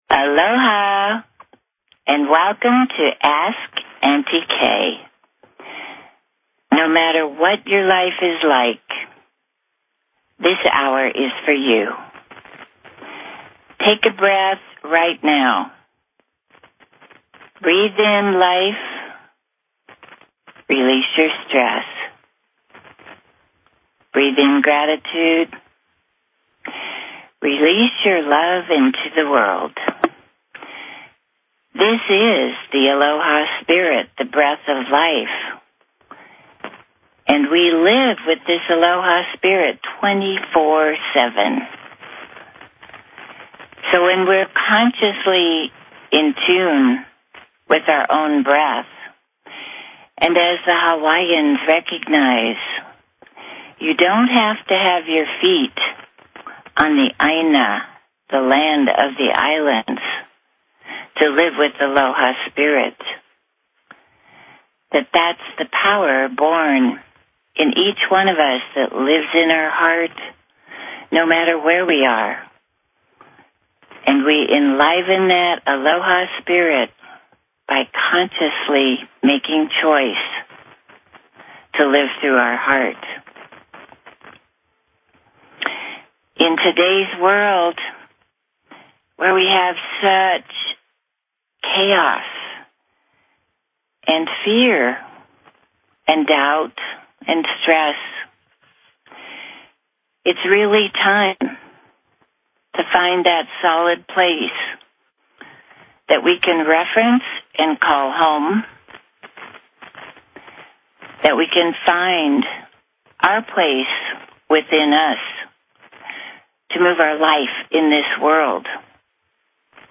Talk Show Episode, Audio Podcast, Global_Family_Live and Courtesy of BBS Radio on , show guests , about , categorized as